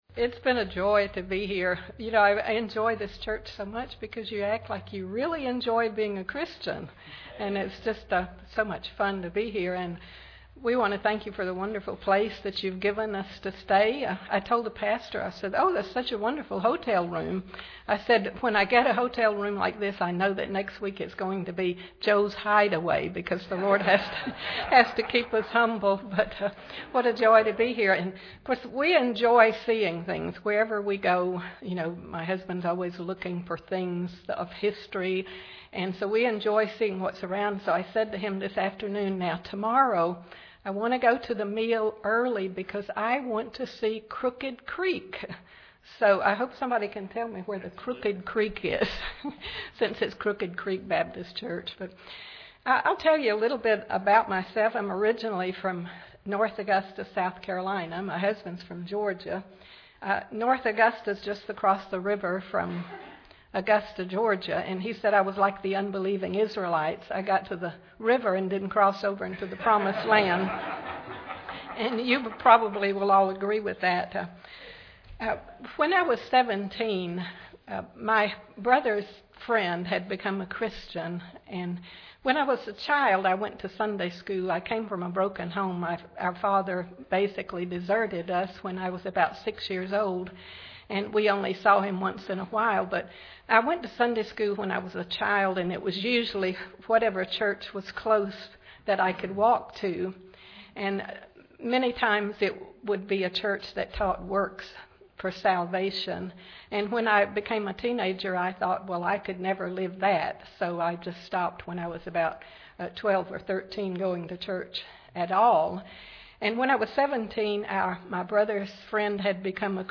2013 Missions Conference Service Type: Special Service Preacher